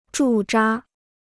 驻扎 (zhù zhā): to station; to garrison (troops).